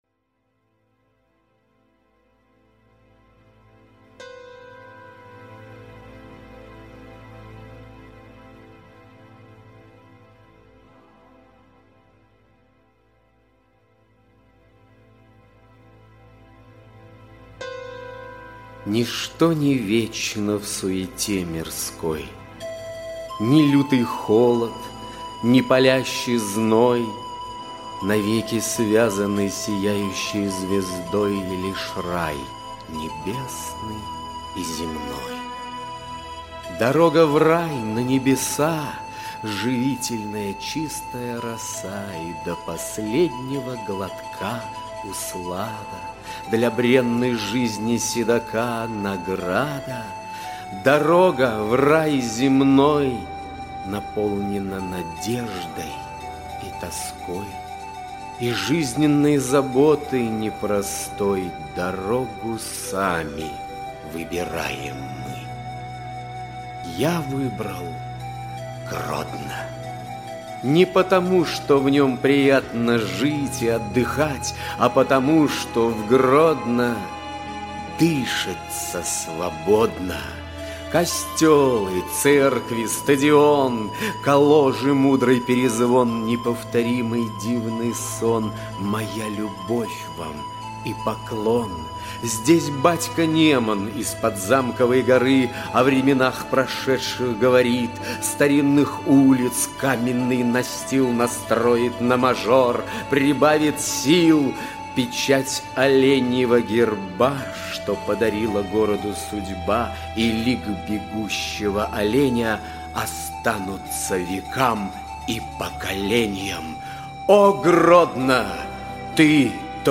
Аудиокнига Мосты надежды, веры и любви | Библиотека аудиокниг